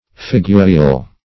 Figurial \Fi*gu"ri*al\, a. Represented by figure or delineation.